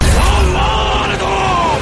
Download Japanese Anime sound effect for free.